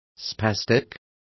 Complete with pronunciation of the translation of spastic.